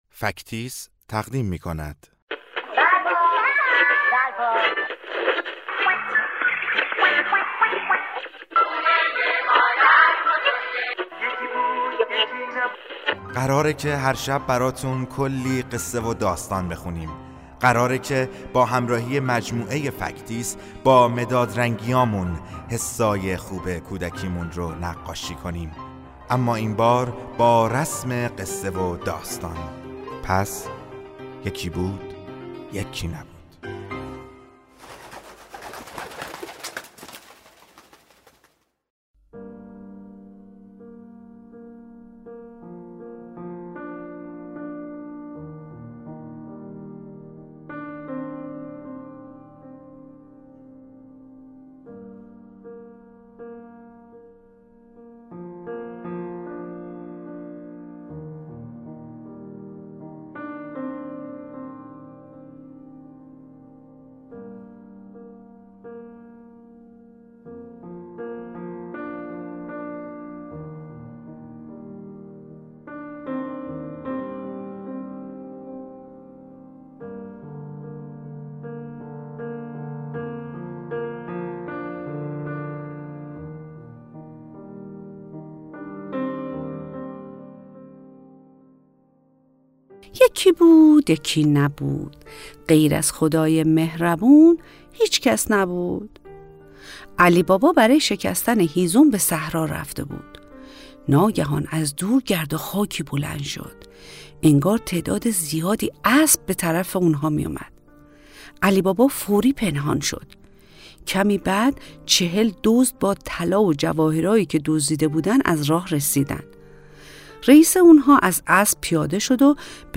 قصه کودکانه صوتی علی بابا و چهل دزد بغداد